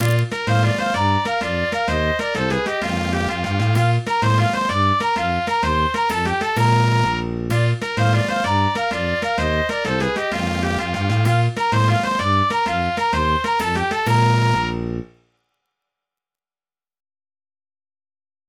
MIDI 5.67 KB MP3